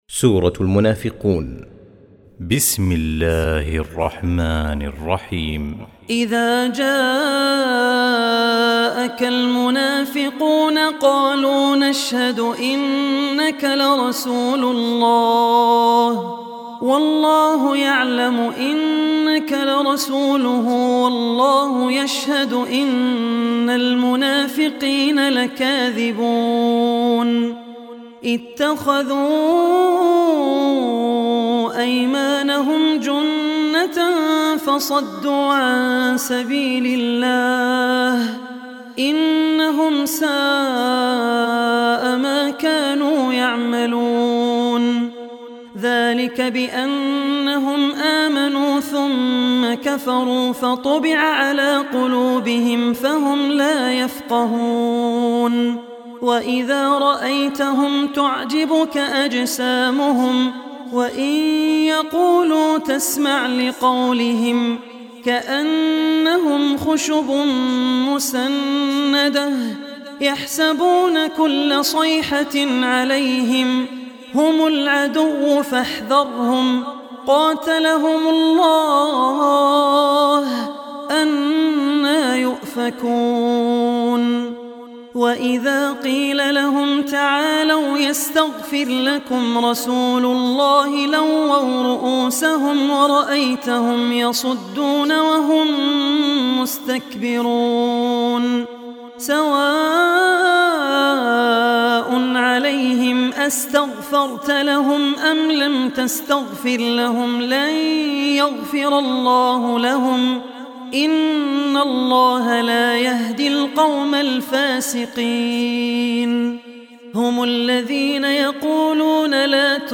Surah Munafiqun Recitation by Abdur Rehman Al Ossi
Surah Al-Munafiqun, listen online mp3 tilawat / recitation in the beautiful voice of Sheikh Abdur Rehman Al Ossi.